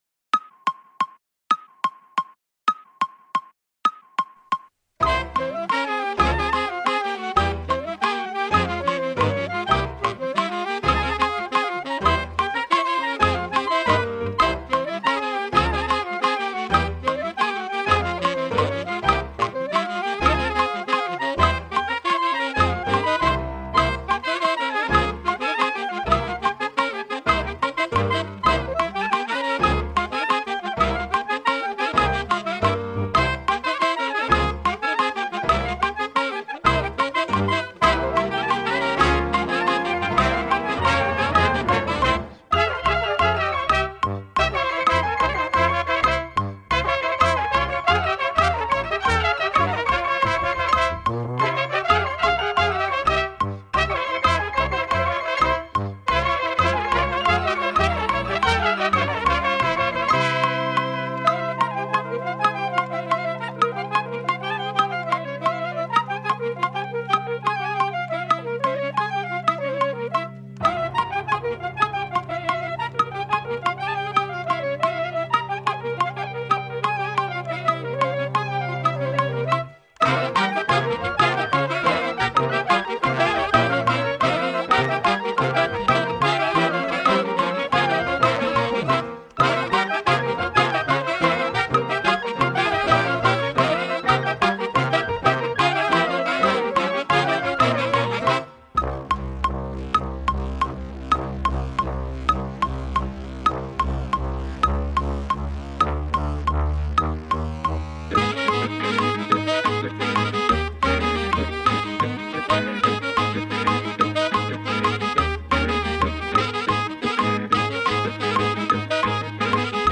Épreuve imposée de batterie FC2 2025
Playback (tempo 80 %)